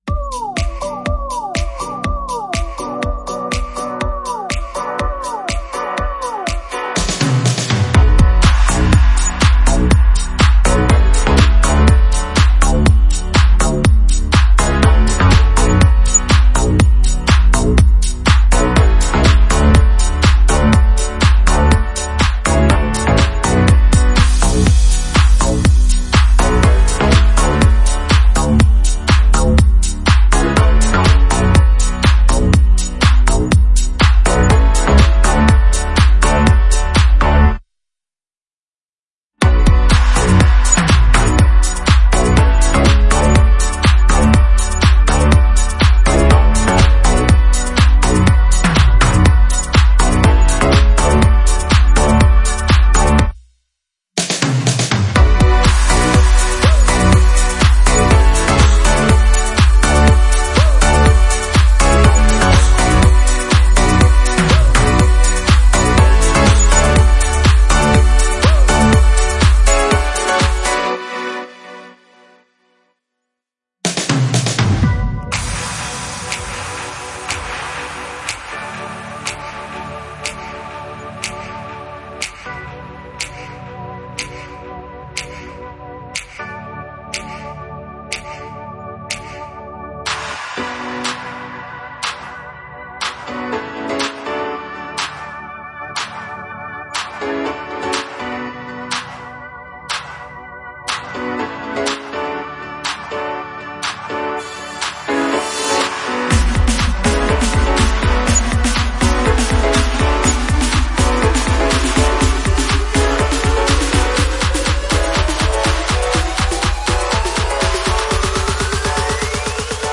Club Instrumental Mix